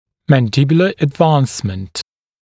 [ˌmæn’dɪbjulə əd’vɑːnsmənt][ˌмэн’дибйулэ эд’ва:нсмэнт]выдвижение вперед нижней челюсти